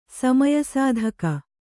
♪ samaya sādhaka